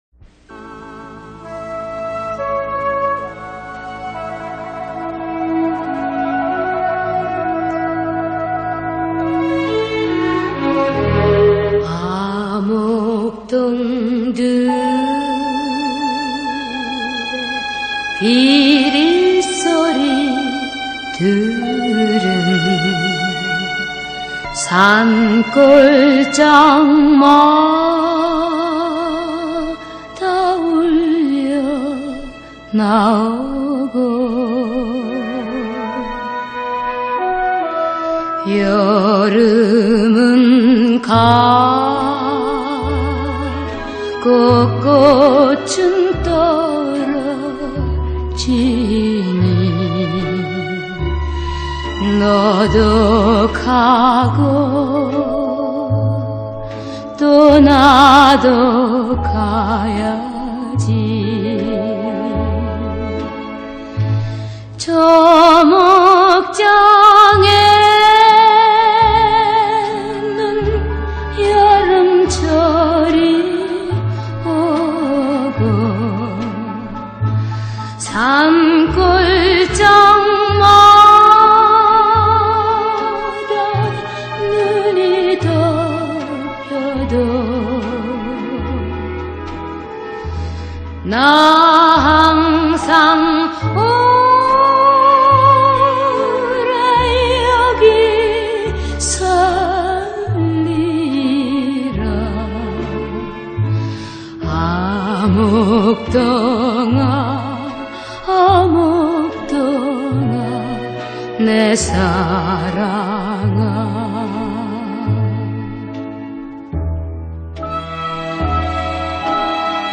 코러스